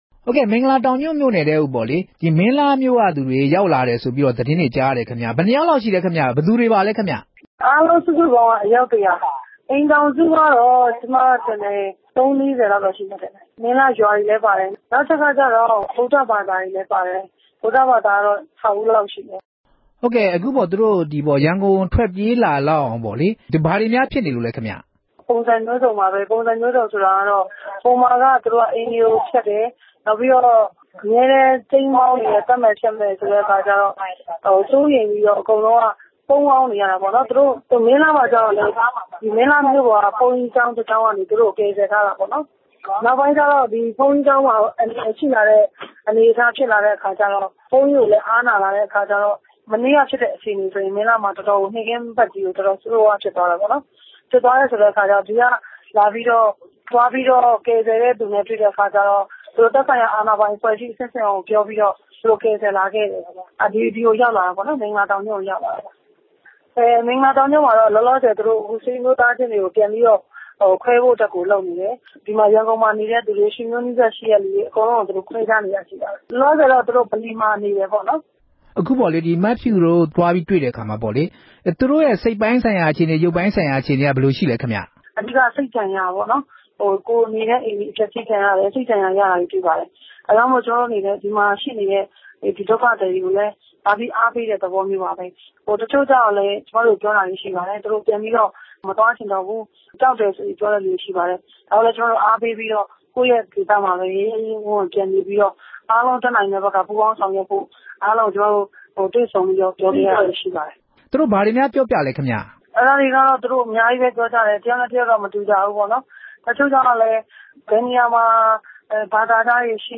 ဒေါ်ဖြူဖြူသင်းနှင့် ဆက်သွယ်မေးမြန်းချက်